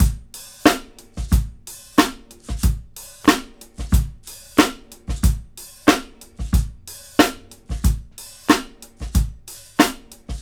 • 92 Bpm Drum Beat A Key.wav
Free drum loop - kick tuned to the A note. Loudest frequency: 1476Hz
92-bpm-drum-beat-a-key-iT8.wav